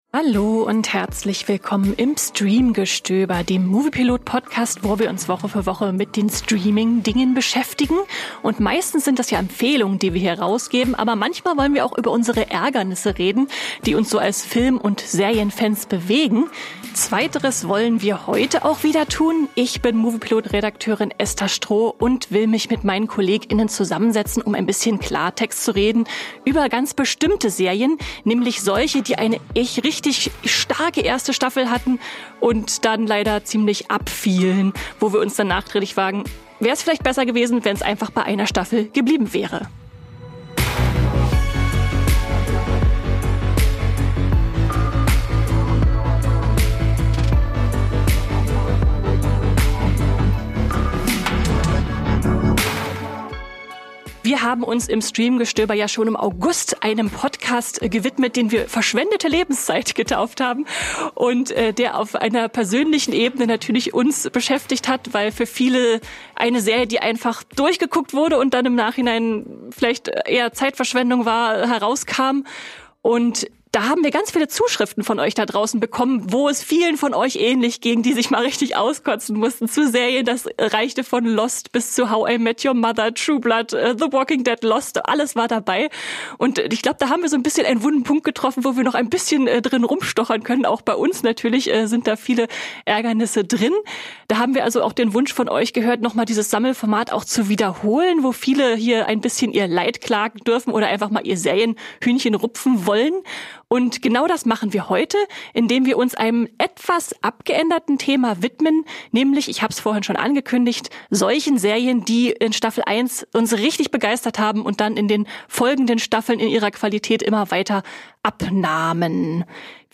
Im Gespräch über persönliche Serien-Ärgernisse reden wir im Moviepilot-Team über Geschichten, die uns in Staffel 1 begeisterten, nur um dann ab Staffel 2 in ihrer Qualität massiv nachzulassen.